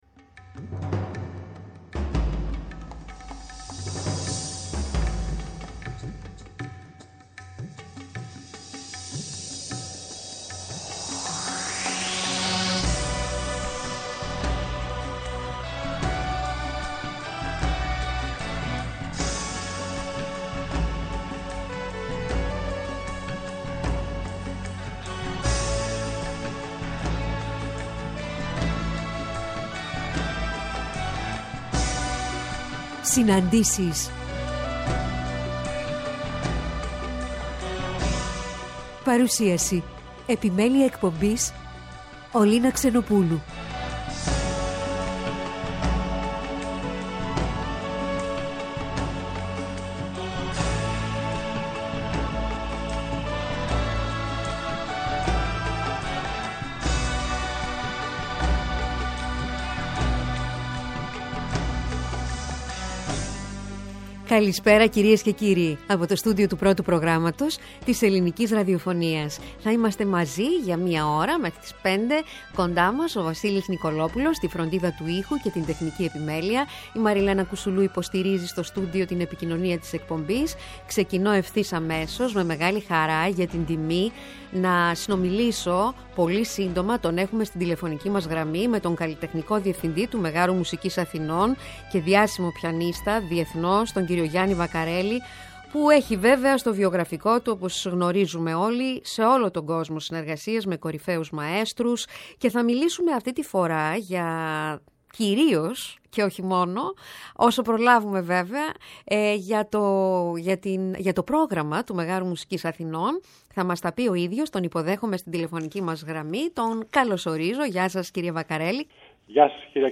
Ο καλλιτεχνικός Διευθυντής του Μεγάρου Μουσικής Αθηνών και διάσημος πιανίστας διεθνώς κ. Γιάννης Βακαρέλης που έχει στο βιογραφικό του εμφανίσεις σε όλον τον κόσμο, συνεργασίες με κορυφαίους μαέστρους (Simon Rattle, Lorin Maazel, Kurt Masur, Mstislav Rostropovich, Yehudi Menuhin) και σημαντικά ορχηστρικά σύνολα, σε μία συνομιλία για το ΜΜΑ και το καλλιτεχνικό του πρόγραμμα για την περίοδο 2022-2023. Με αναφορές στην πλούσια καλλιτεχνική του πορεία.